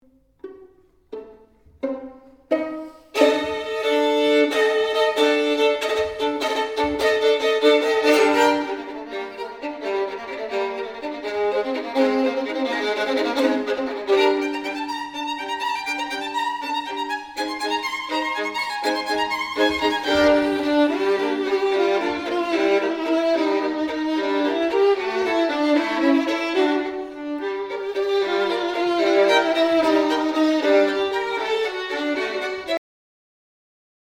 Viool
Ik ben de kleinste telg uit de strijkersfamilie met als grote broers de altviool, de cello en de contrabas.